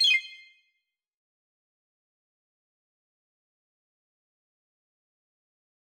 confirm_style_4_006.wav